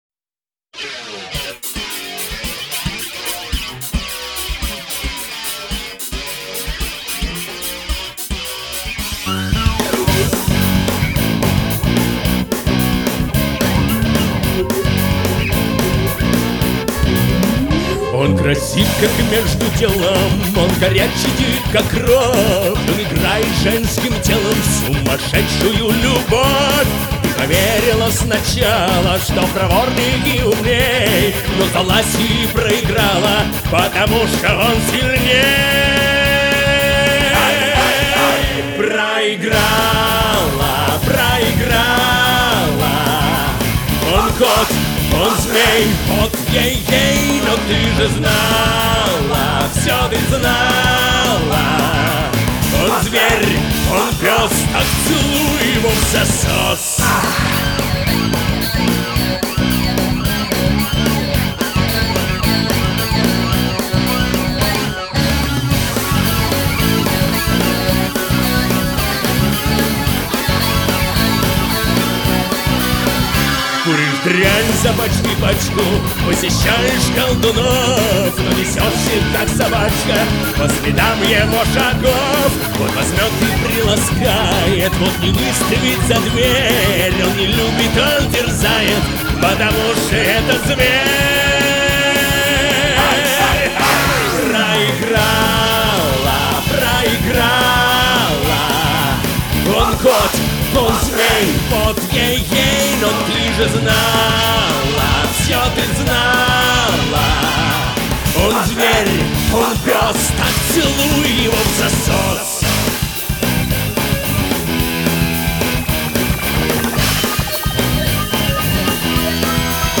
барабаны
гусли, бэк-вокал.